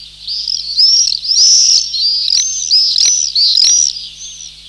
Eurasian Tree Sparrow
Eurasian-Tree-Sparrow.mp3